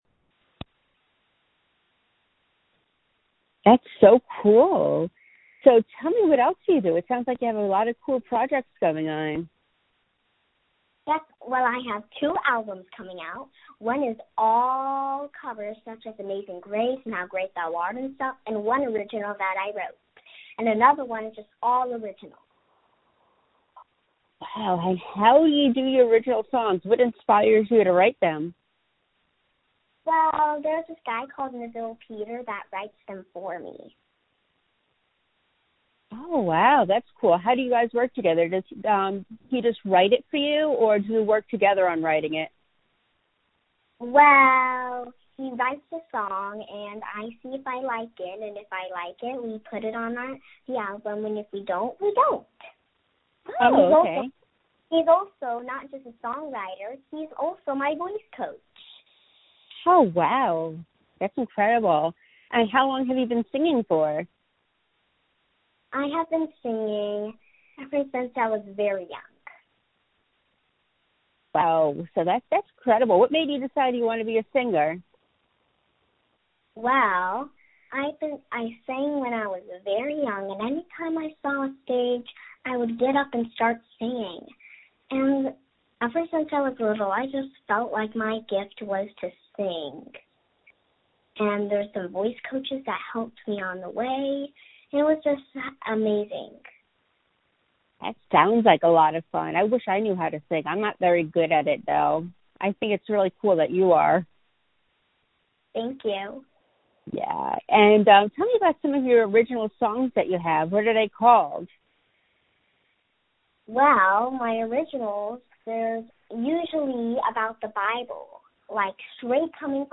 Interviews Music